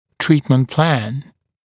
[‘triːtmənt plæn][‘три:тмэнт плэн]план лечение